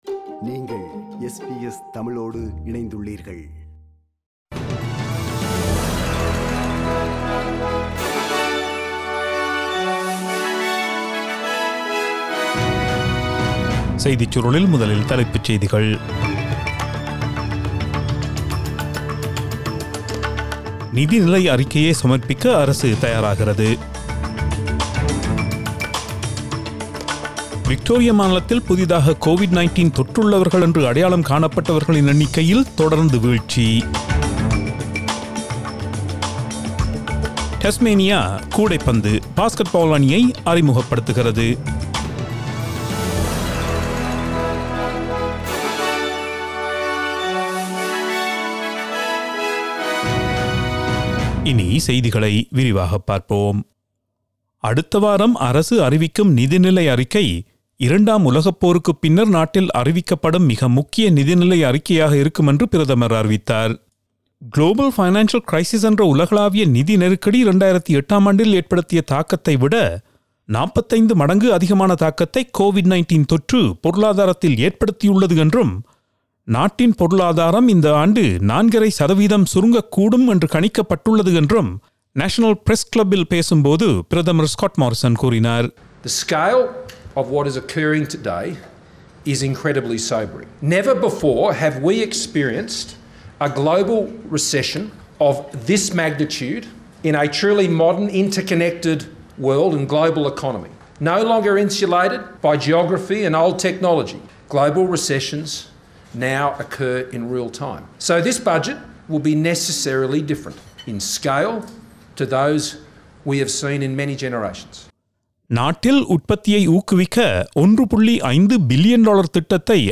Australian news bulletin for Thursday 01 October 2020.